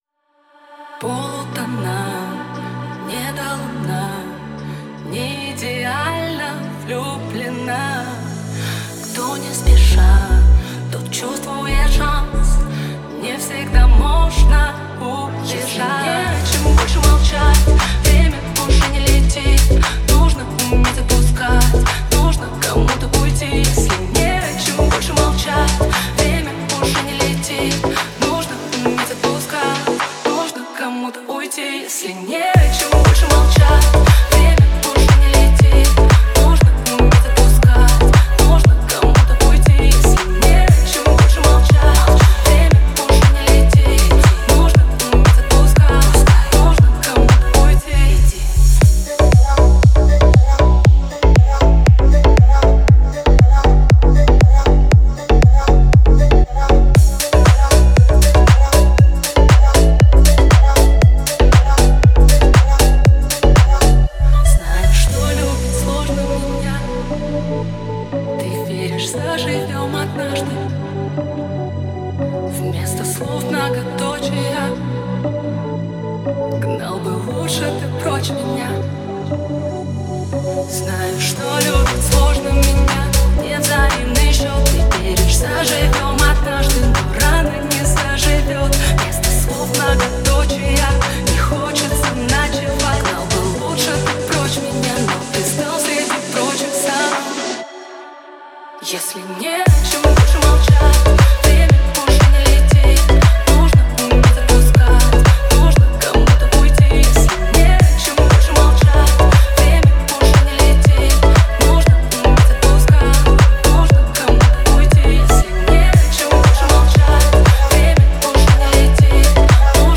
мелодичные вокалы